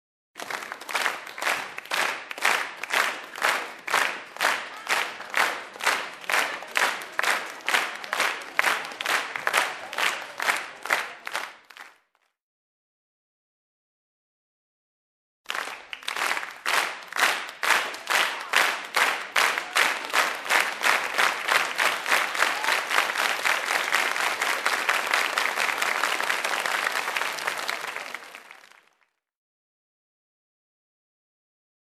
Звуки ликования
Звуки аплодисментов в едином ритме